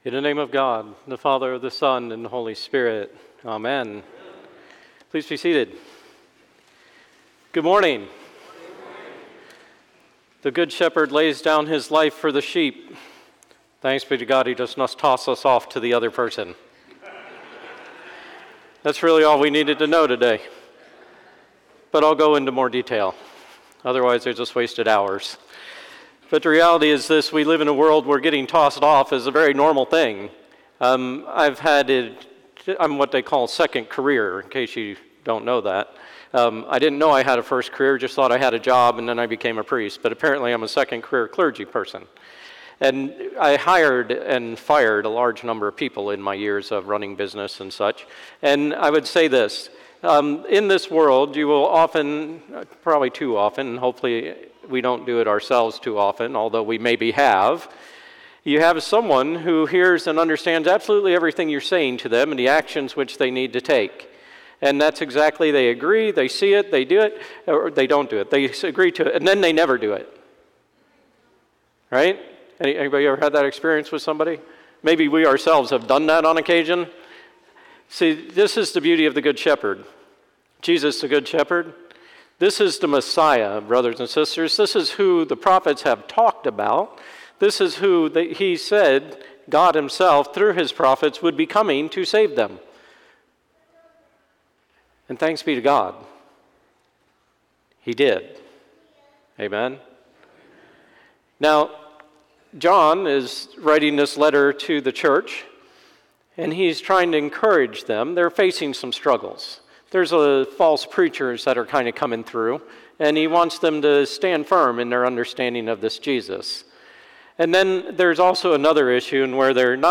Sermon 4/21/24 Fourth Sunday of Easter - Holy Innocents' Episcopal Church